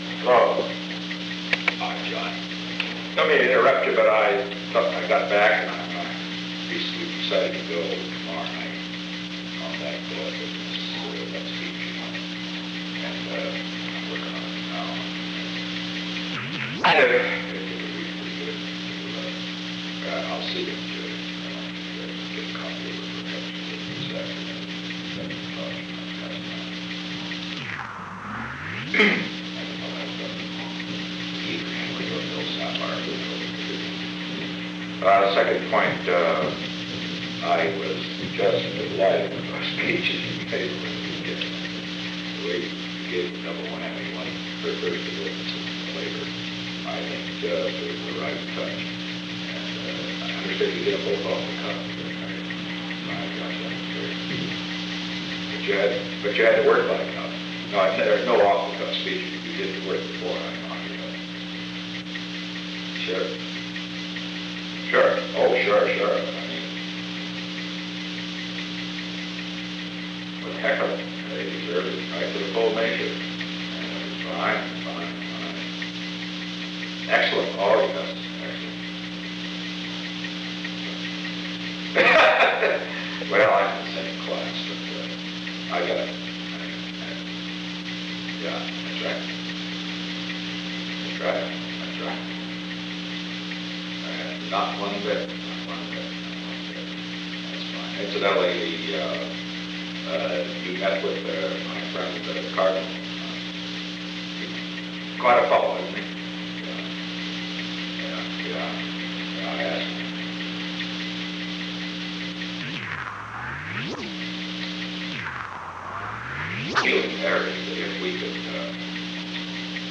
On January 24, 1972, President Richard M. Nixon and John B. Connally met in the President's office in the Old Executive Office Building from 3:23 pm to 3:29 pm. The Old Executive Office Building taping system captured this recording, which is known as Conversation 317-012 of the White House Tapes.